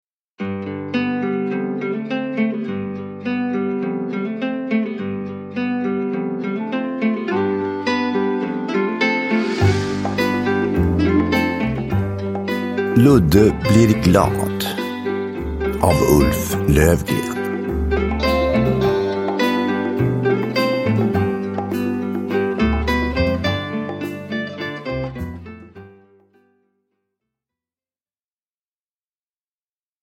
Ludde blir glad – Ljudbok – Laddas ner